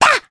Rephy-Vox_Jump_jp.wav